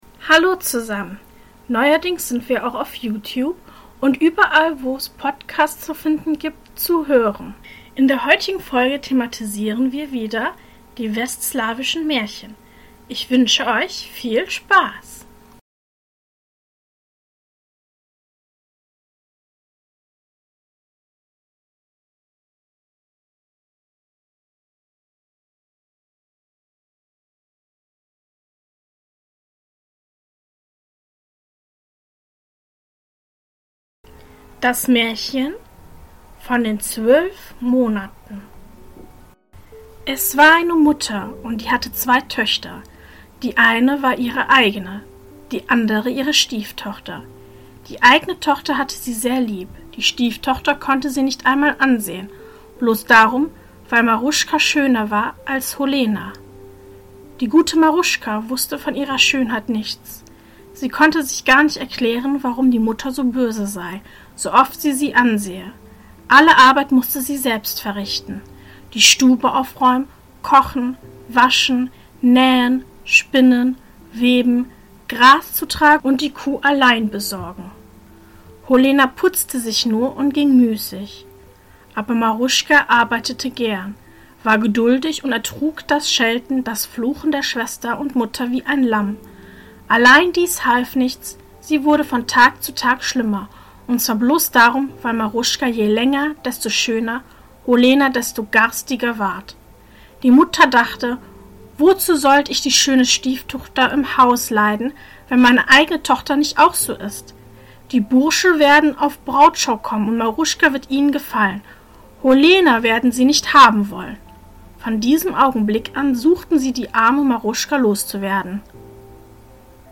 In der heutigen Folge lese ich Folgendes vor: 1. Von den zwölf Monaten 2. Die Waise im Radhost.